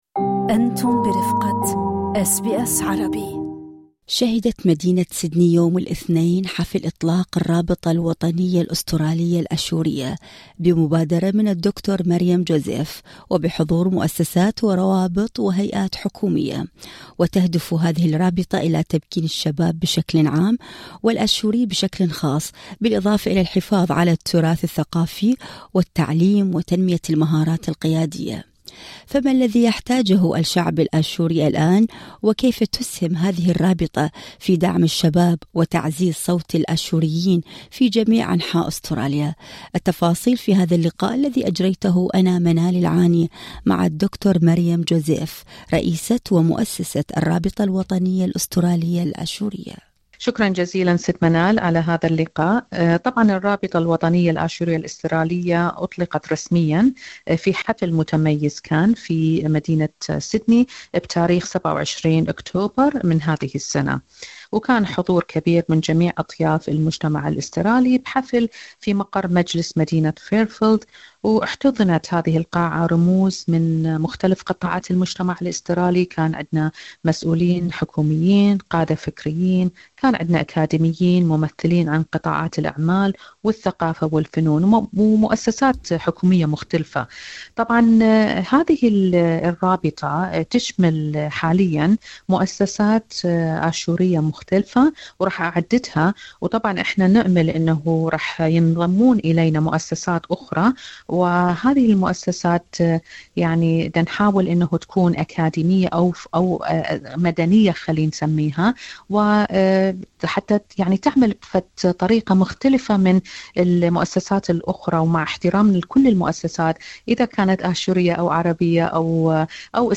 التفاصيل في اللقاء الصوتي